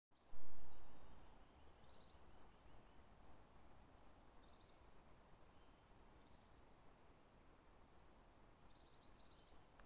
Птицы -> Курообразные ->
рябчик, Bonasa bonasia
СтатусПоёт